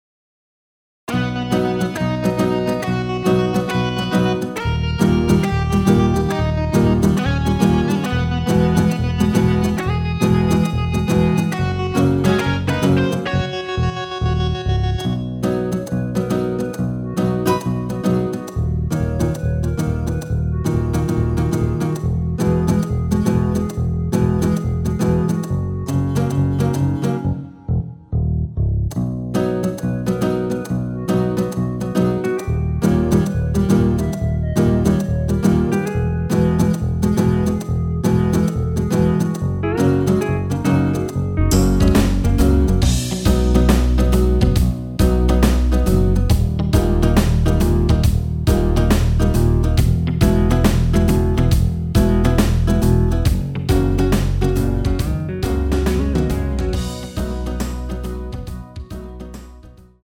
원키에서(-3)내린 멜로디 포함된 MR입니다.
앞부분30초, 뒷부분30초씩 편집해서 올려 드리고 있습니다.
중간에 음이 끈어지고 다시 나오는 이유는